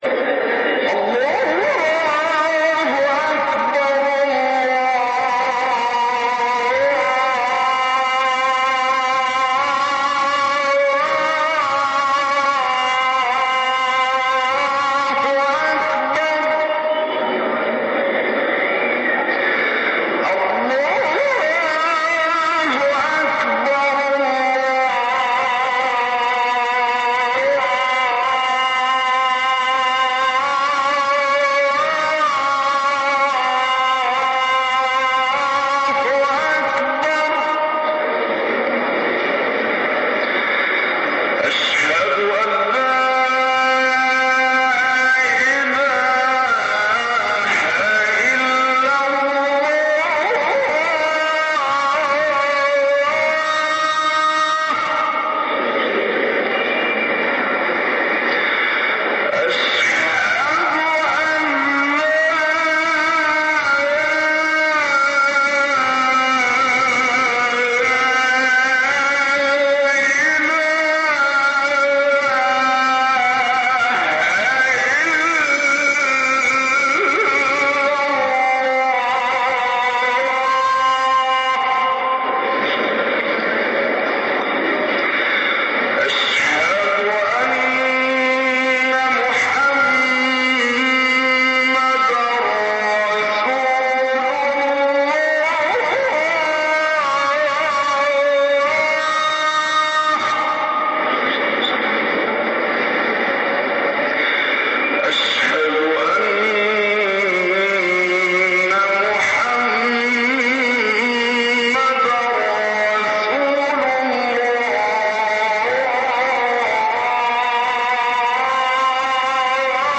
أناشيد ونغمات
عنوان المادة أذان-22